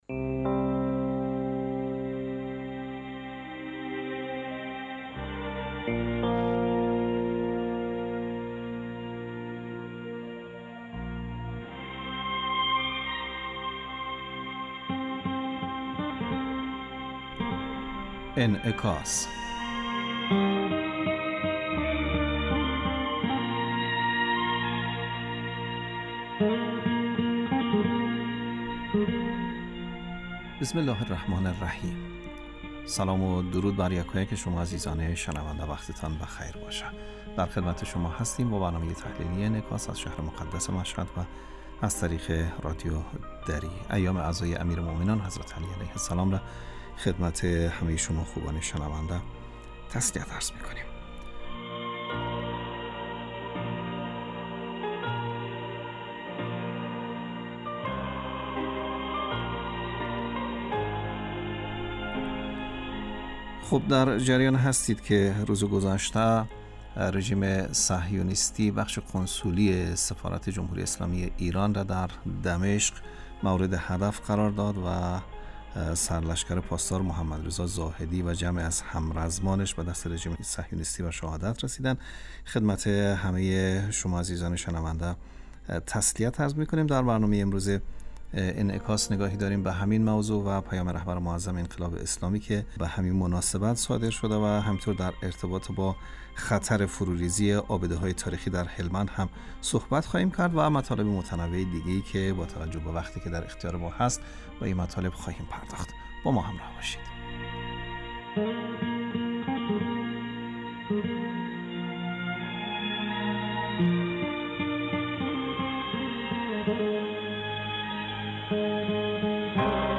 برنامه انعکاس به مدت 30 دقیقه هر روز در ساعت 06:50 بعد از ظهر (به وقت افغانستان) بصورت زنده پخش می شود. این برنامه به انعکاس رویدادهای سیاسی، فرهنگی، اقتصادی و اجتماعی مربوط به افغانستان، برخی از خبرهای مهم جهان و تحلیل این رویدادها می پردازد.